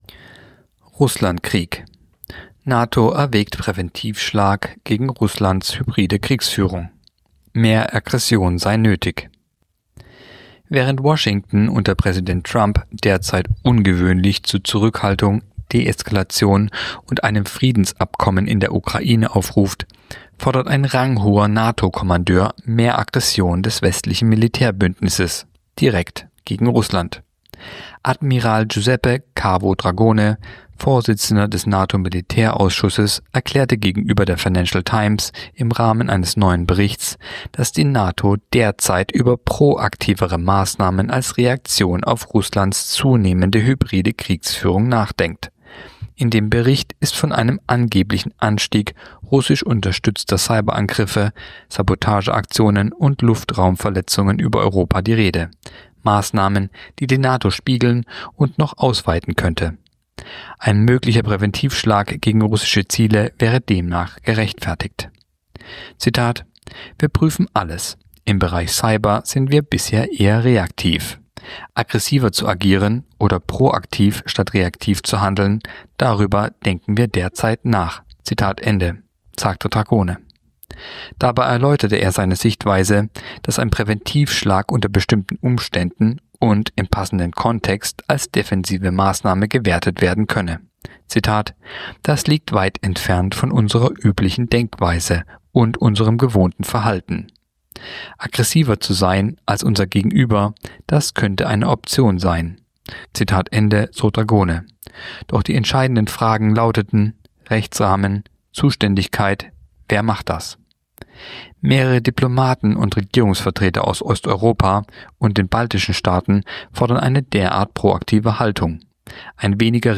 Kolumne der Woche (Radio)Nato erwägt „Präventivschlag“ gegen Russlands hybride Kriegsführung